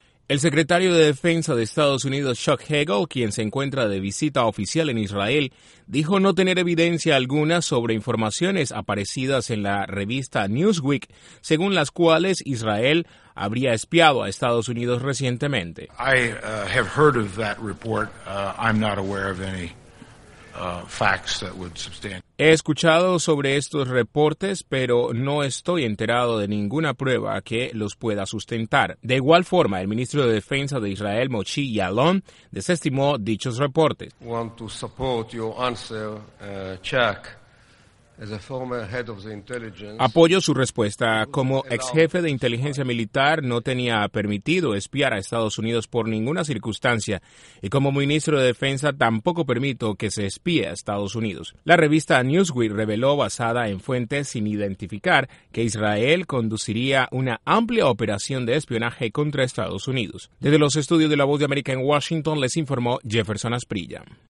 El jefe del Pentágono dijo no tener evidencias sobre presunto espionaje de Israel a Estados Unidos. Desde la Voz de América en Washington informa